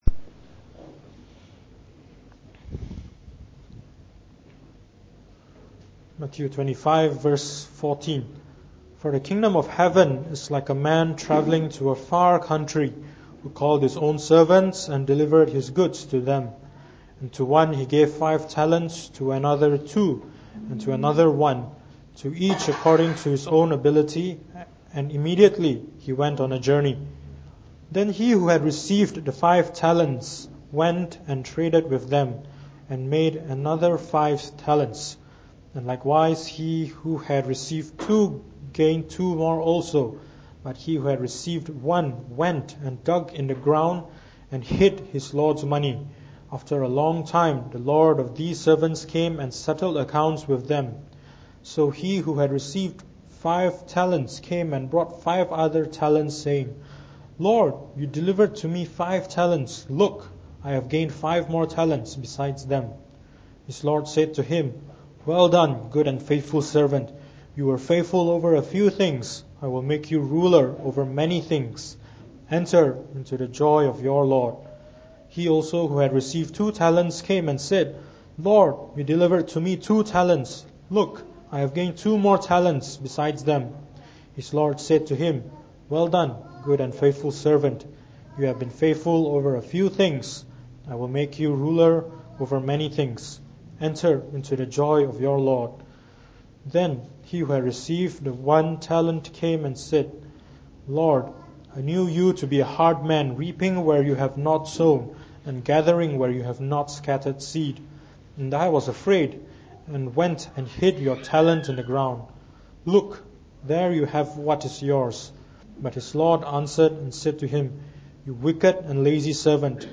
delivered in the Evening Service